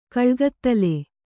♪ kaḷgattale